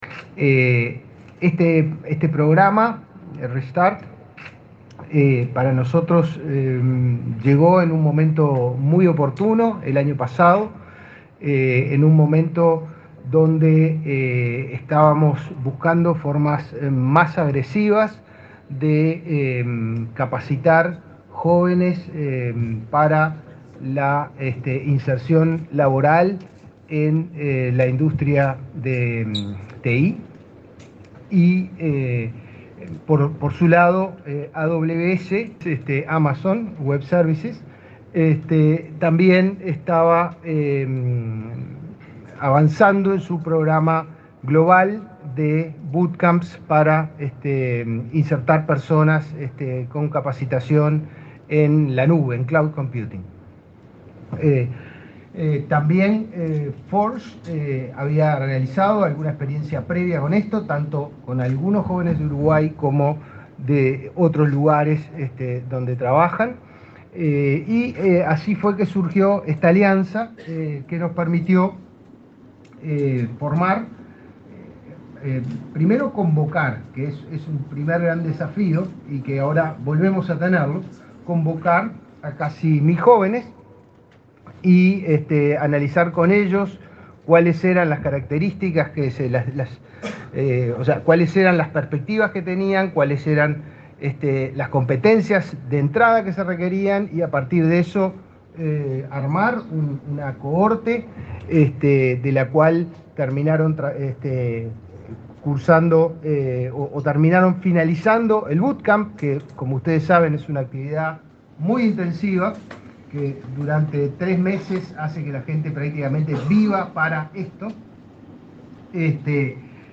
Palabra de autoridades en acto en Inefop
Palabra de autoridades en acto en Inefop 02/08/2023 Compartir Facebook X Copiar enlace WhatsApp LinkedIn El director del Instituto Nacional de Empleo y Formación Profesional (Inefop), Pablo Darscht, y el director nacional de Empleo, Daniel Pérez, participaron del lanzamiento del programa Re-Start de Amazon Web Services, edición 2023.